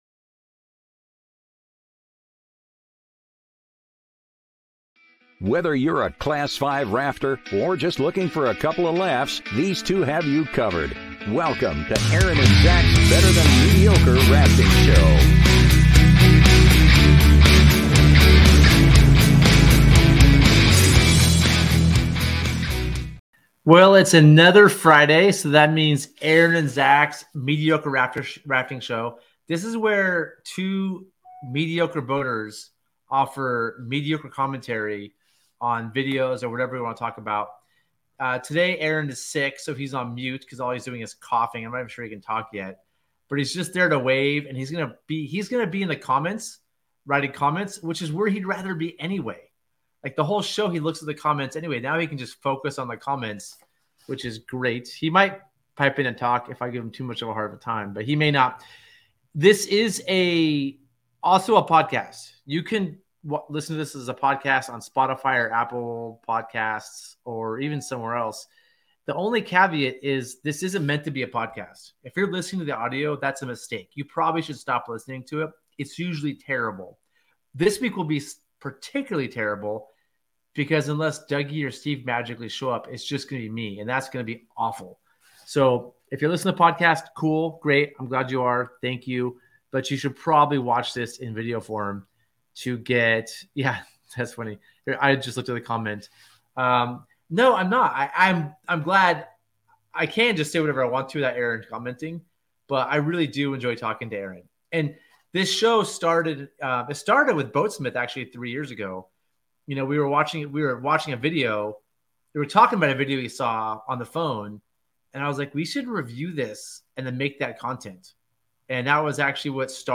Gear Garage Live Show Carnage Videos!!